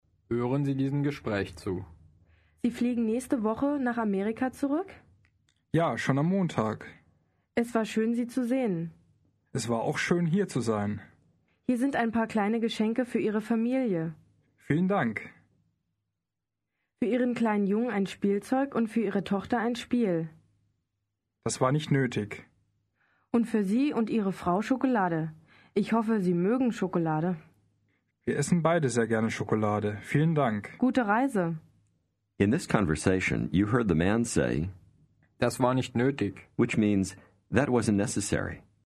Аудио курс для самостоятельного изучения немецкого языка.